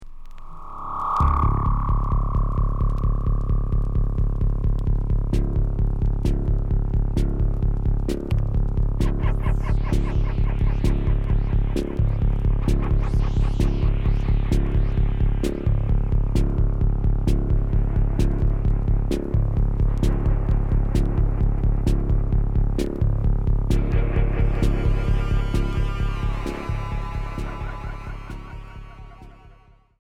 Cold wave expérimentale